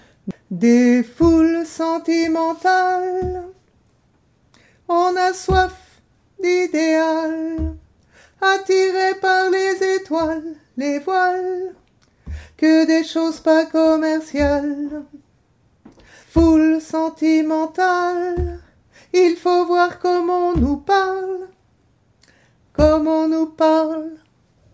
Refrain alti bassz